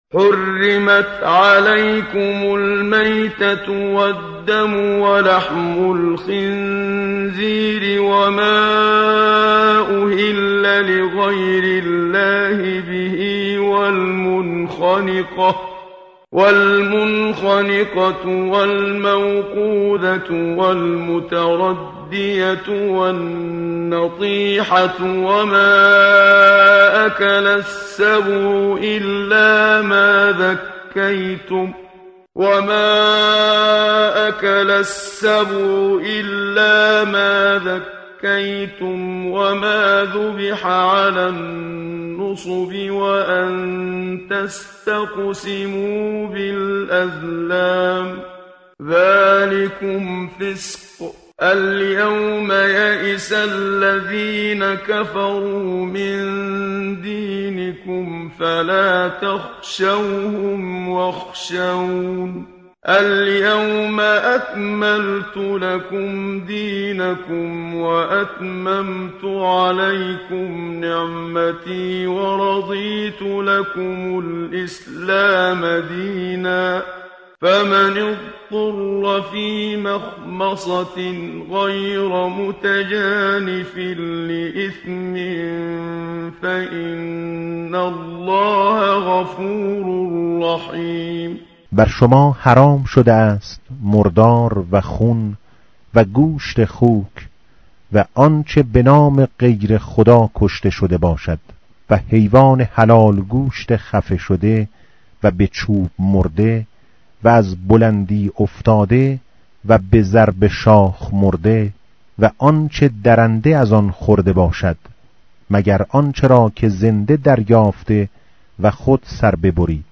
tartil_menshavi va tarjome_Page_107.mp3